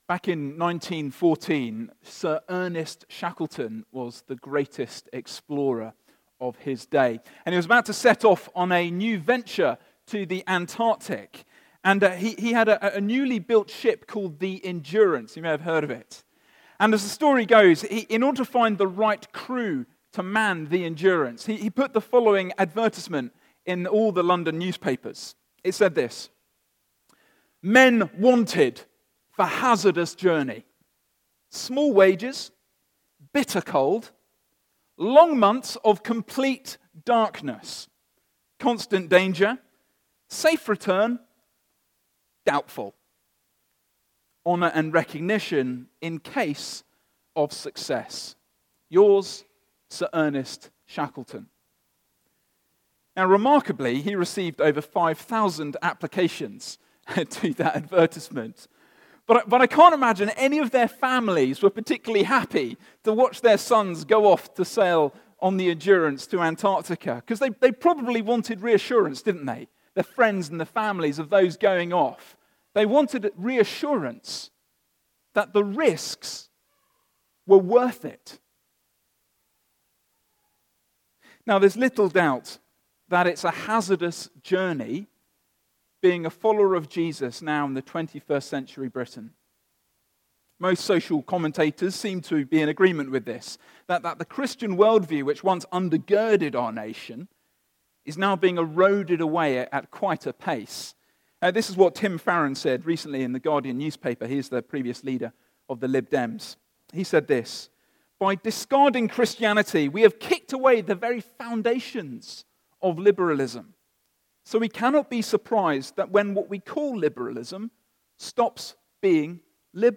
This is the sixth sermon in our Weakness series looking at 2 Corinthians. This weeks verses were 5:11-6:13 and had three main points: